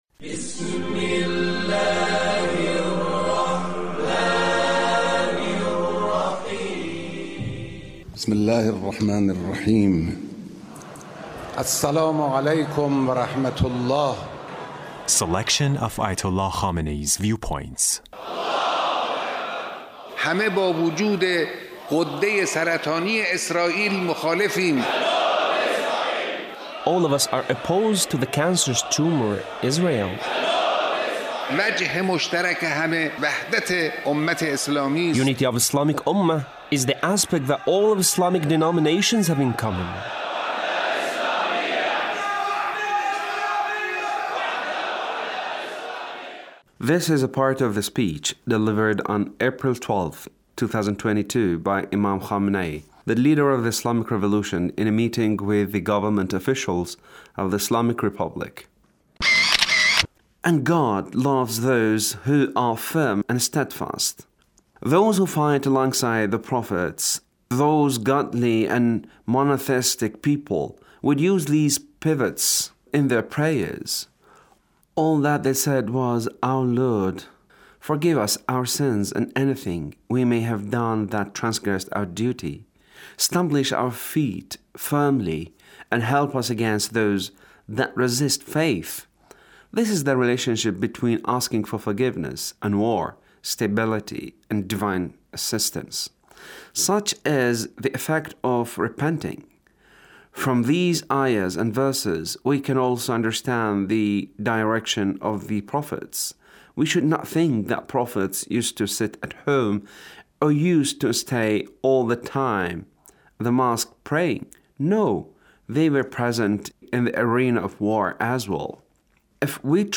The Leader's speech on Ramadhan